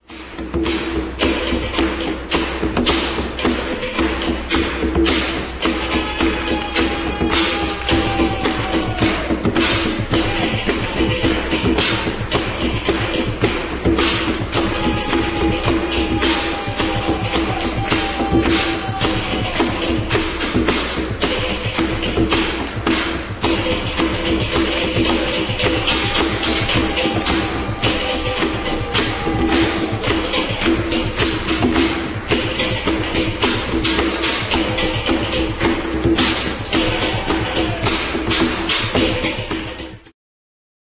Tłum milczy zaklęty.
teatr naturalnego dźwięku,
flet prosty, dholak
gitary, bęben,
tabla, gongi, instrumenty perkusyjne